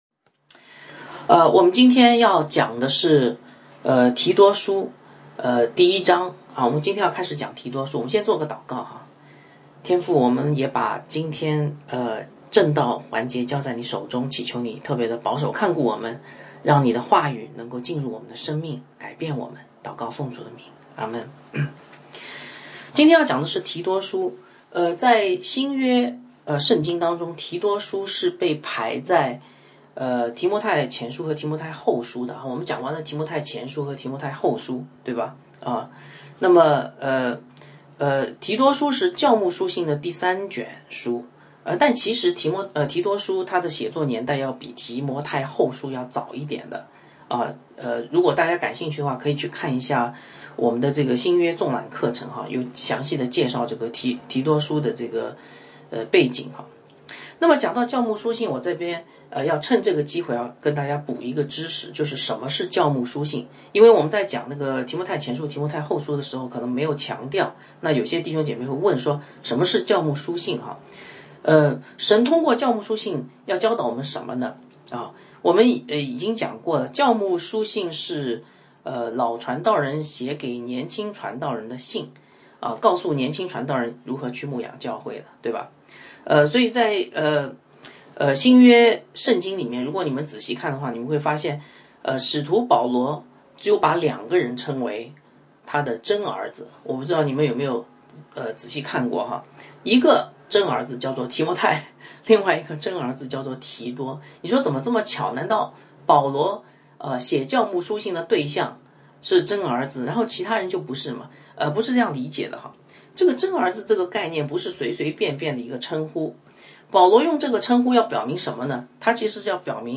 讲道